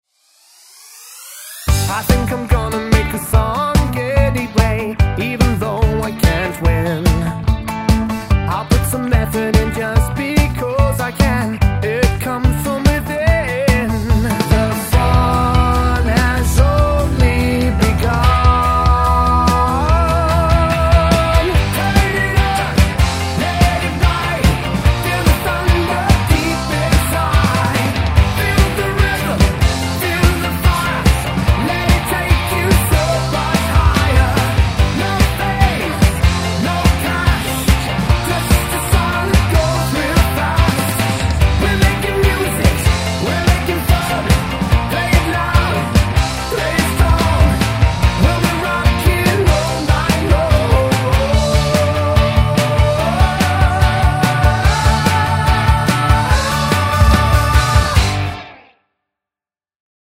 Music / Rock
Really incredible voice, what a treat.
This one I threw together in a few hours so the mixing is not perfect but meh!
a bit of rockabilly
I love doing harmonies in my music.